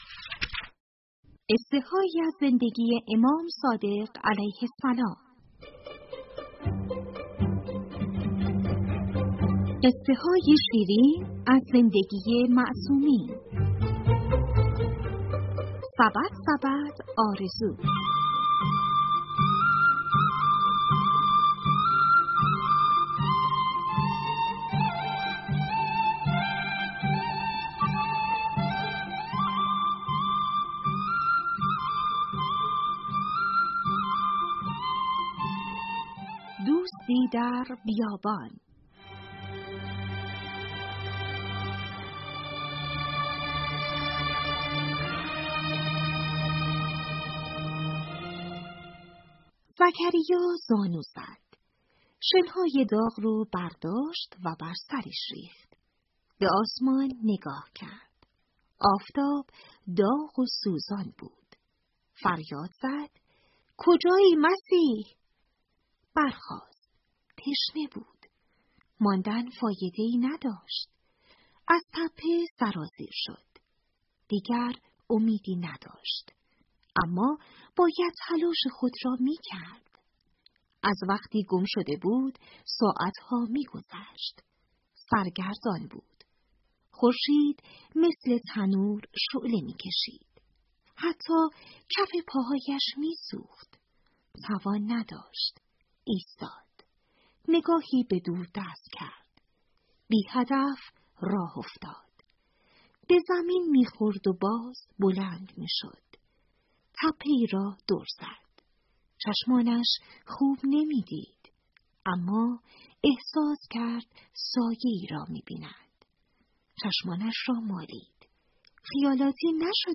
کتاب صوتی
# قصه کودک # کتابخوانی # روایتگری # حضرت امام صادق علیه السلام # کتاب صوتی # پادکست